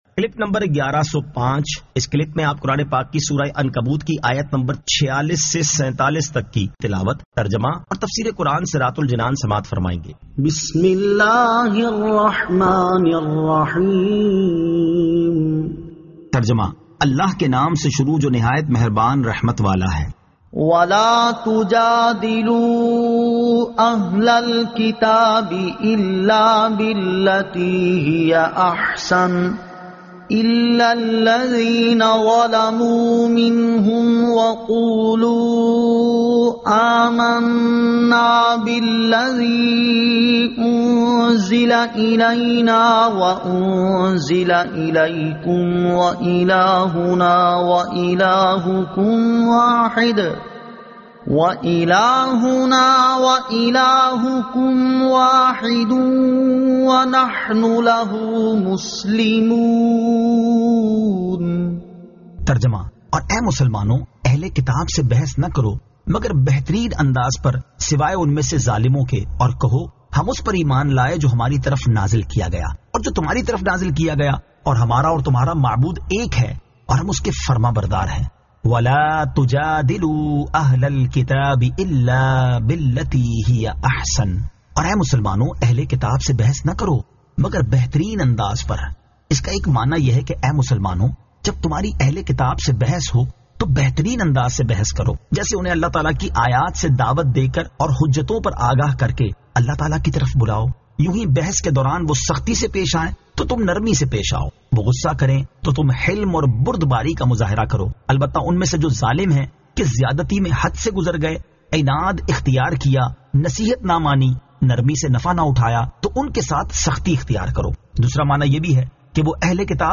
Surah Al-Ankabut 46 To 47 Tilawat , Tarjama , Tafseer